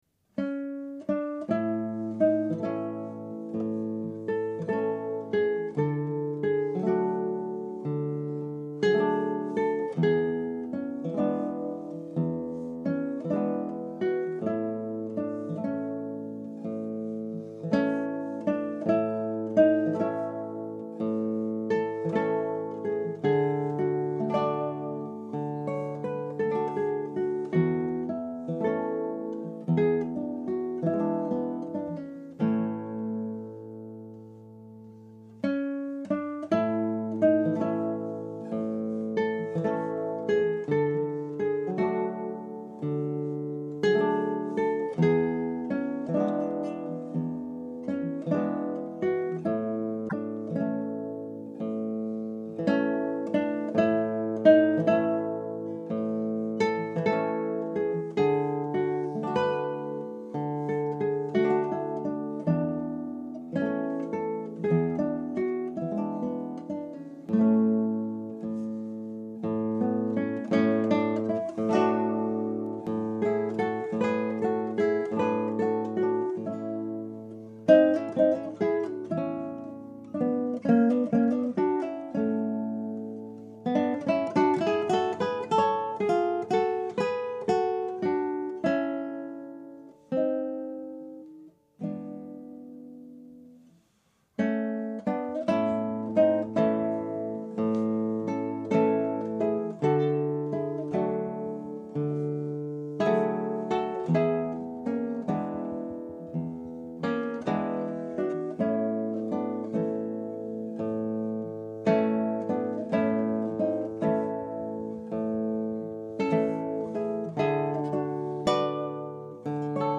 Scraps from the Operas arranged for Two Guitars
Scrap 1: Andantino.
Scrap 2 (2:34): Allegro.
This is the up-tempo closing section, called a "cabaletta", to the previous duet.